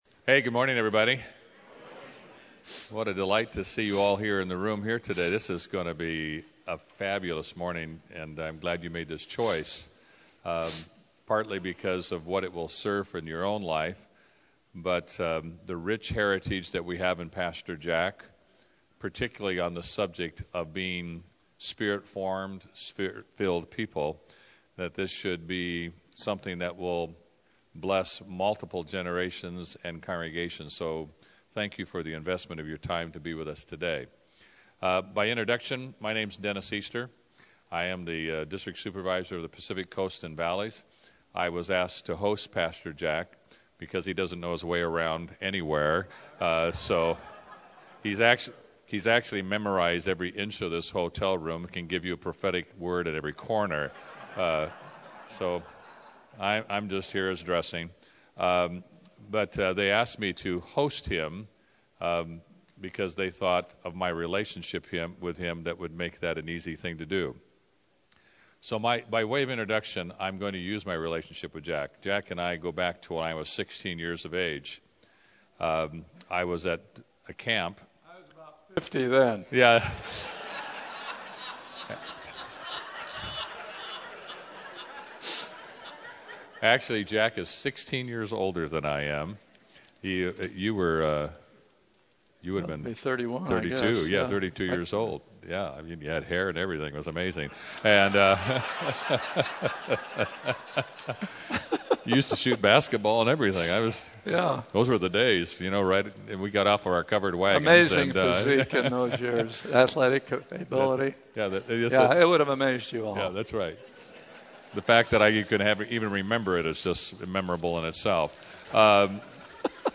JackHayford_Convention2014_MultiplySpiritLife.mp3